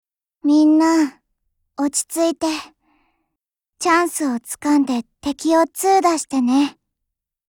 Cv-20102_warcry.mp3